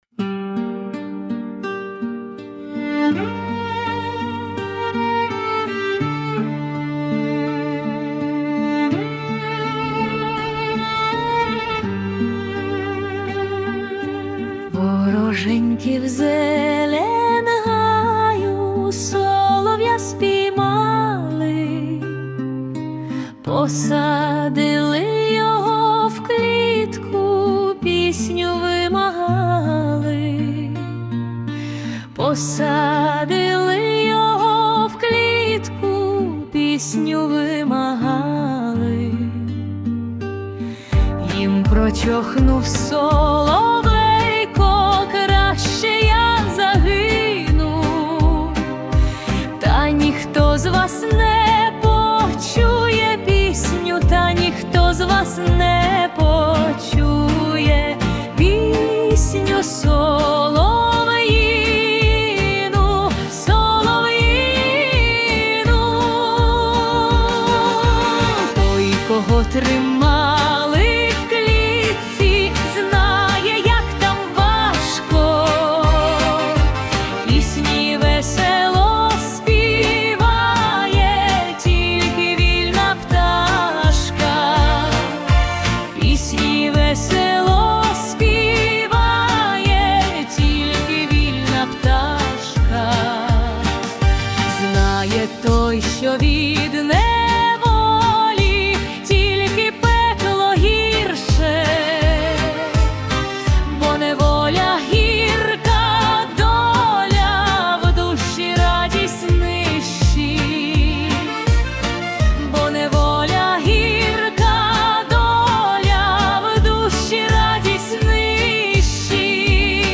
Гарненька пісня. Патріотично, мелодійно.